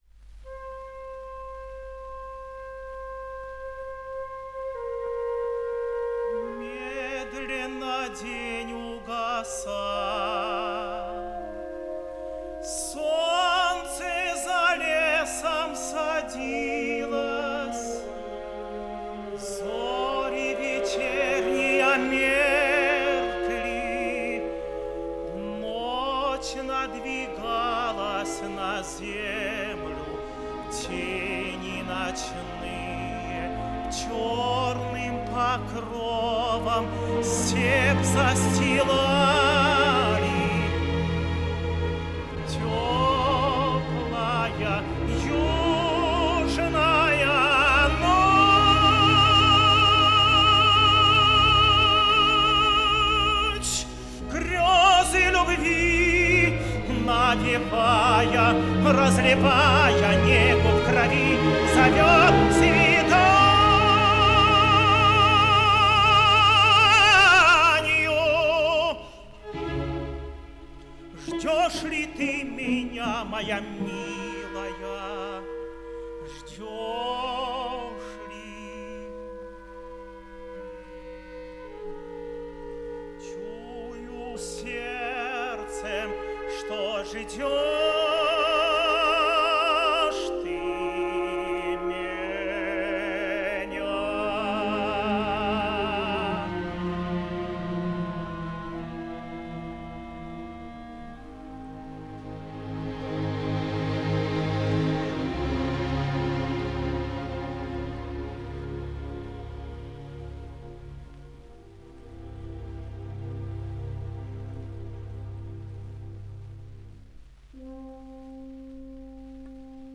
Иван Козловский - Речитатив и каватина Владимира Игоревича (А.П.Бородин. Князь Игорь) (1949)